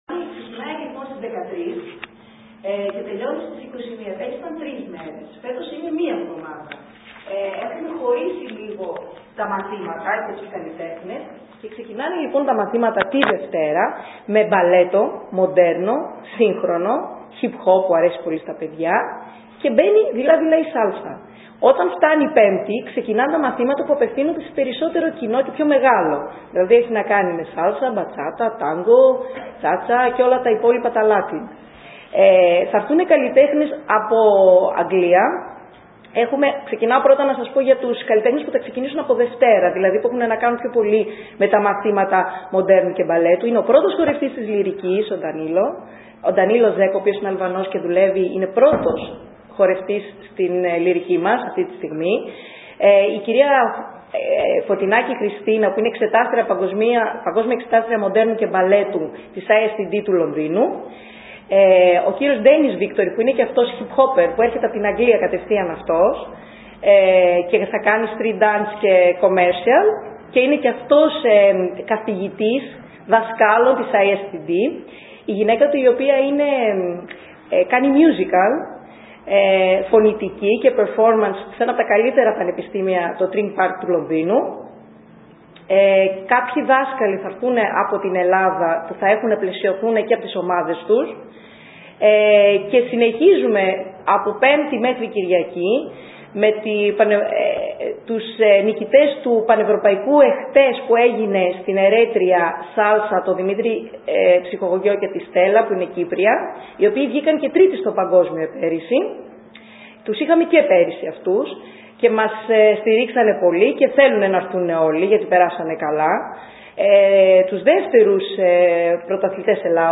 Συνέντευξη τύπου